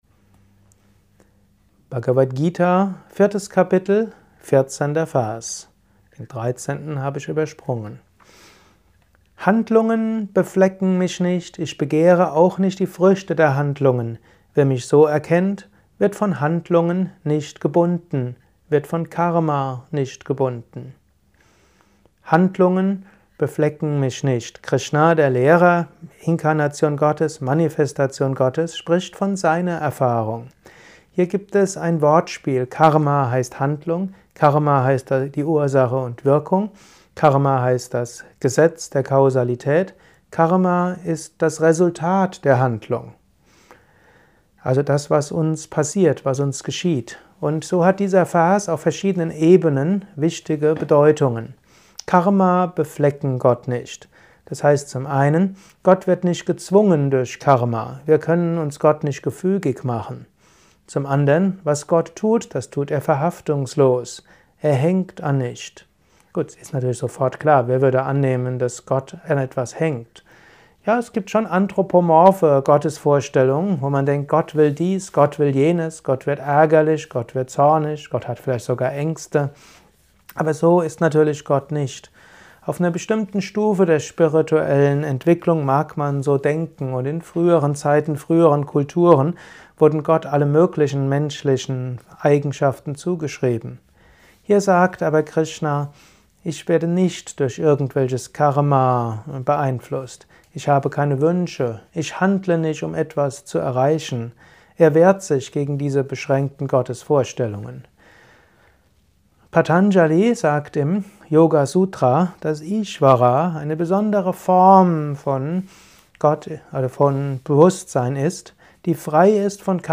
Kurzvorträge
Aufnahme speziell für diesen Podcast.